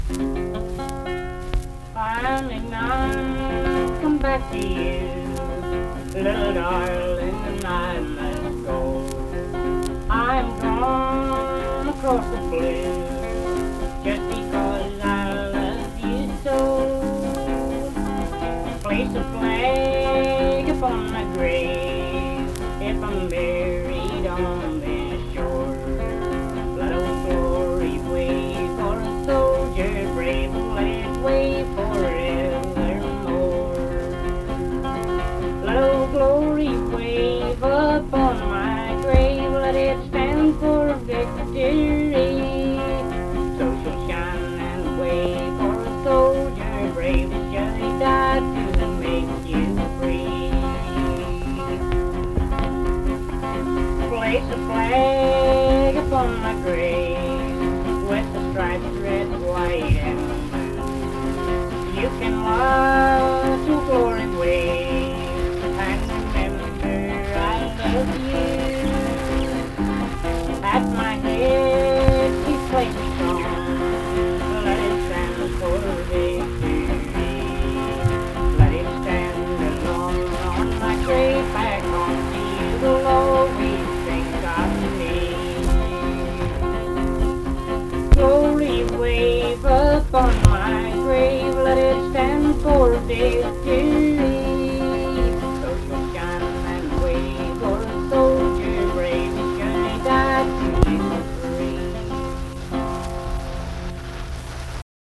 Unaccompanied fiddle music and accompanied (guitar) vocal music
Voice (sung), Guitar